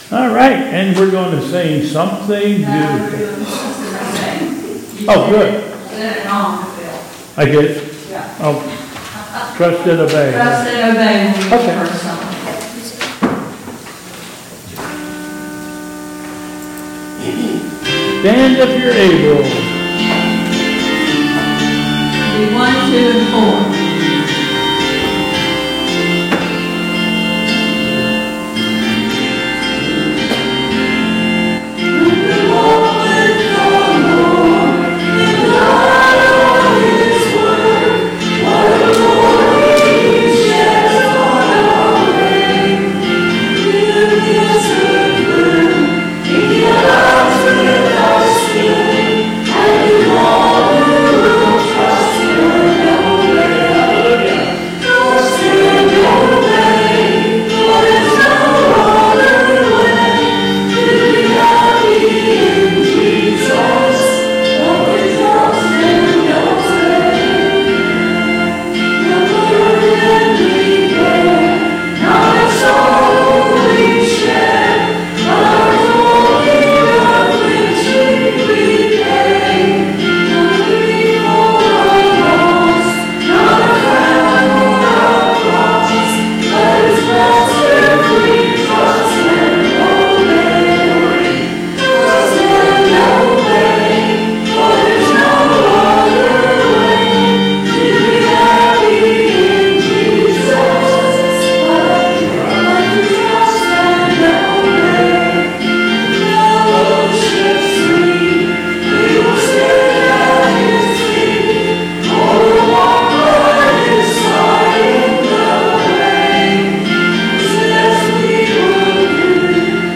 Bethel Church Service
Hymn: "Trust and Obey"